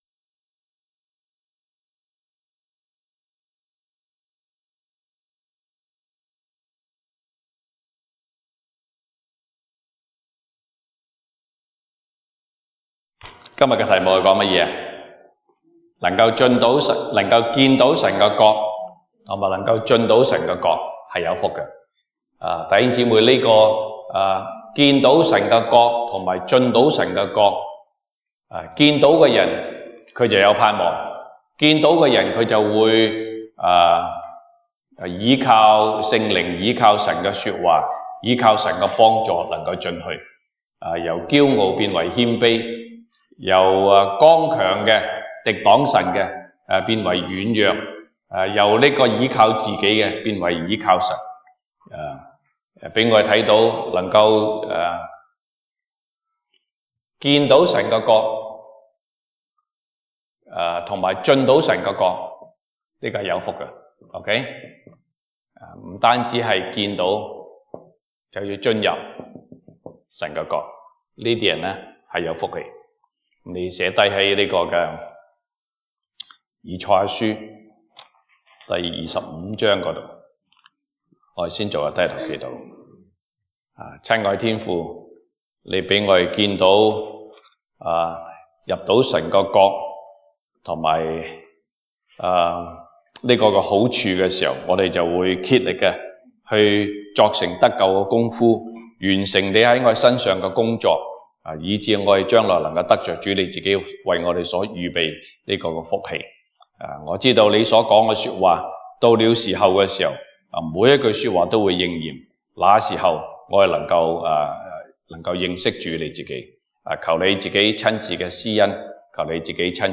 東北堂證道 (粵語) North Side: 能見和能進神的國的人有福
Passage: 以賽亞書 Isaiah 25:1-12 Service Type: 東北堂證道 (粵語) North Side (First Church)